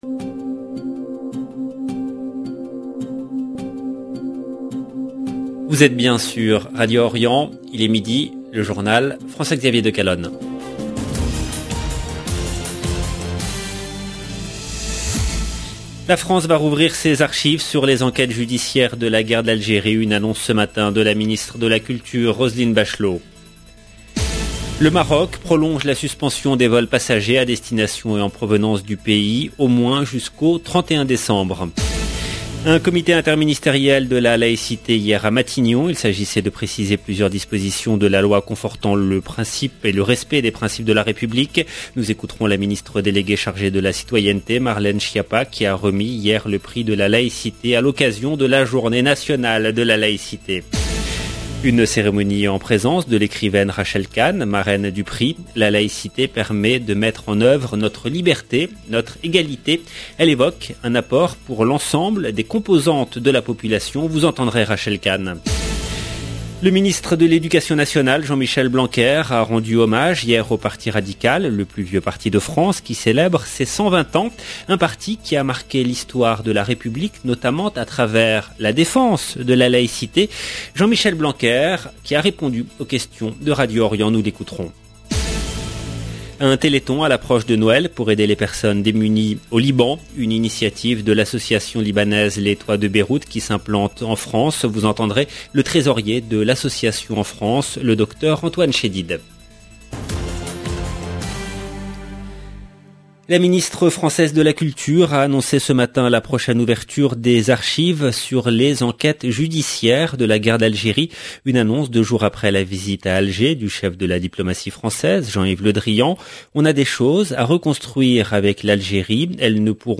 Jean-Michel Blanquer a répondu aux questions de Radio Orient.